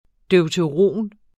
Udtale [ dœwtəˈʁoˀn ] eller [ dεwtəˈʁoˀn ]